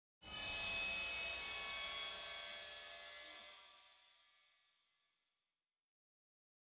Suspense 3 - Stinger 4.wav